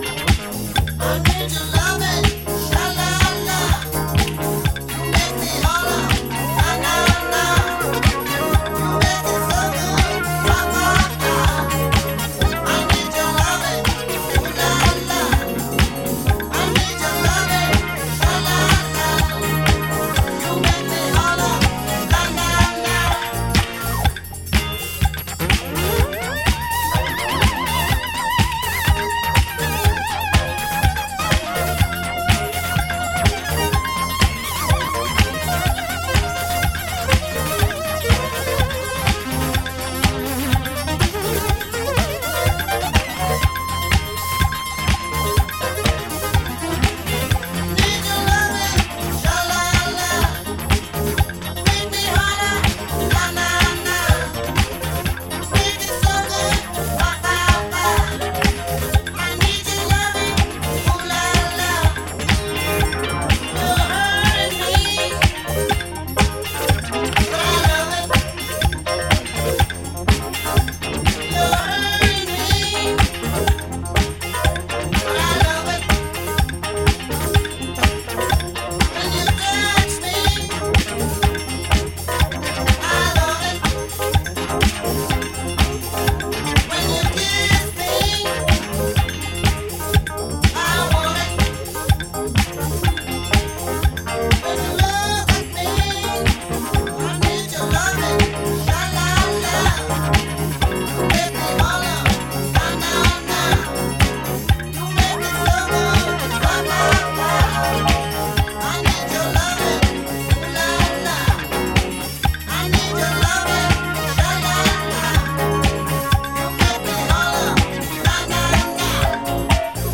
グルーヴィーでドライヴ感のあるトラックに、脱力的でなんとも個性溢れるヴォーカルが最高な一発！